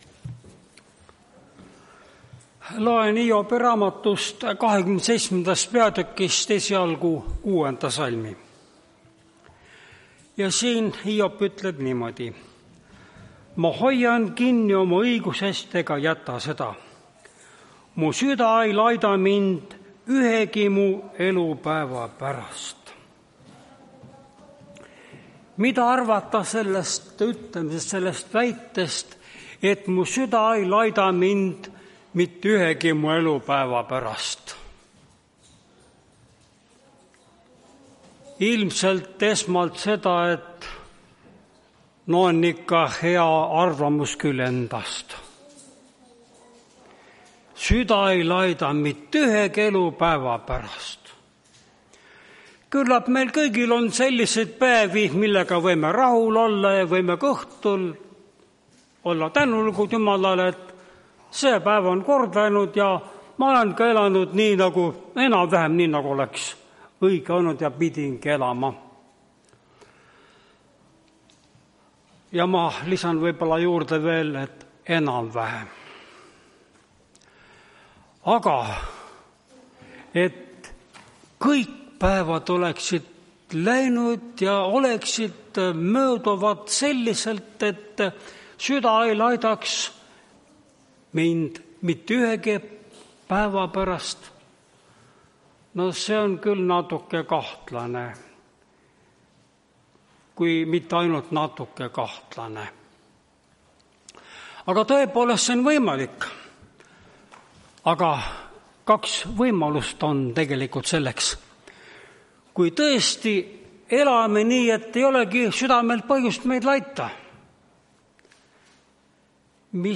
Tartu adventkoguduse 04.10.2025 teenistuse jutluse helisalvestis.